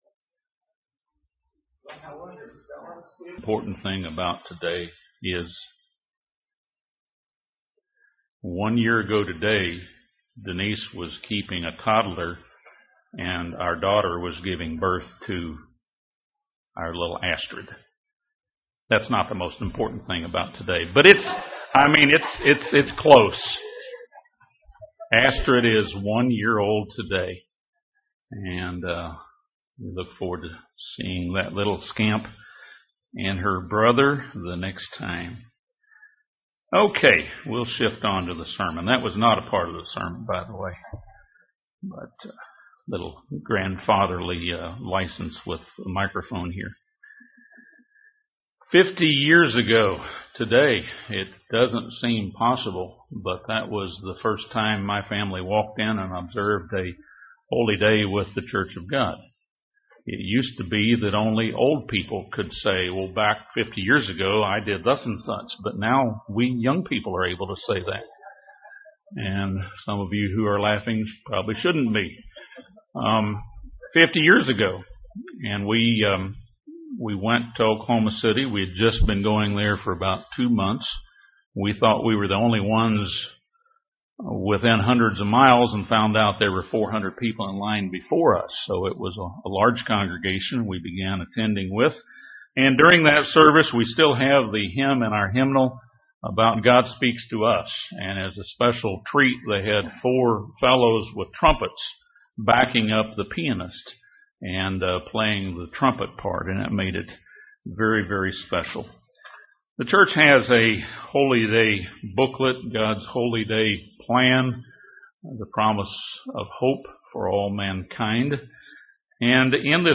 Given in Gadsden, AL Huntsville, AL